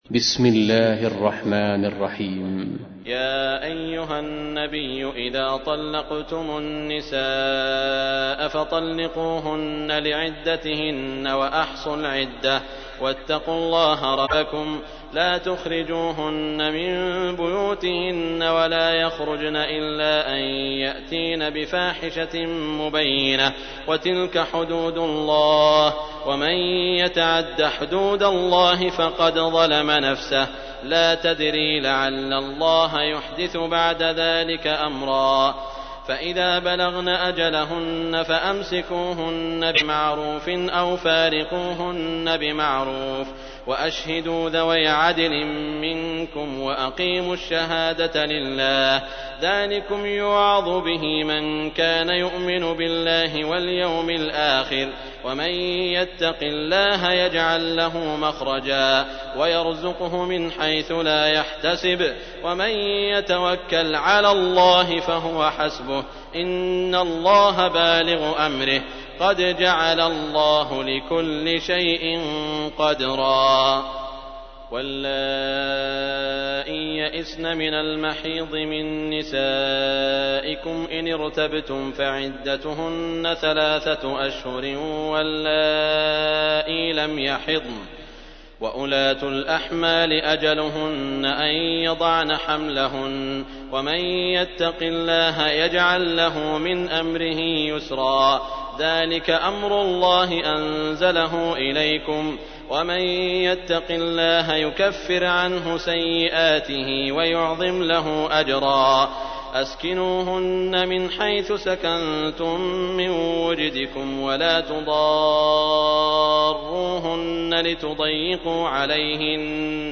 تحميل : 65. سورة الطلاق / القارئ سعود الشريم / القرآن الكريم / موقع يا حسين